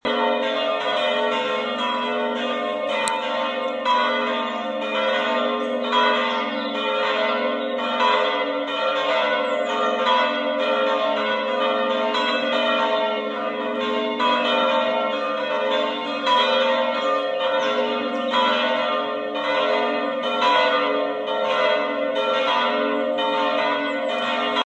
justinuskirche-glockengelaeut.mp3